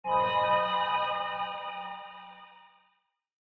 sounds / ambient / cave / cave1.mp3
cave1.mp3